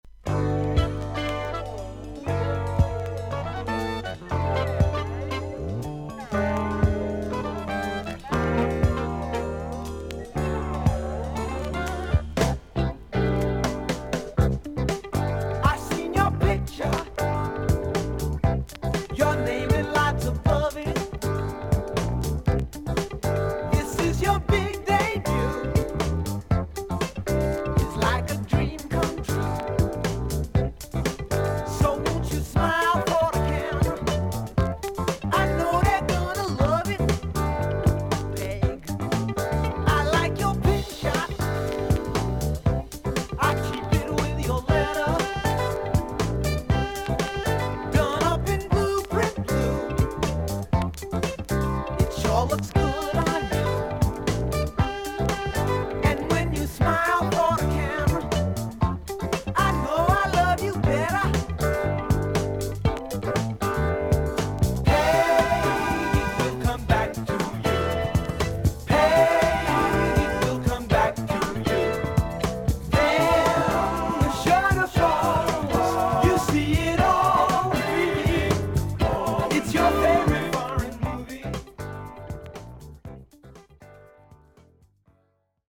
B1序盤にキズあり、1分くらいまで少々周回ノイズとサーフィス・ノイズがあります。
ほか音のグレードはVG++〜VG+:少々軽いパチノイズの箇所あり。クリアな音です。